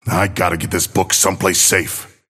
Abrams voice line - I gotta get this book someplace safe.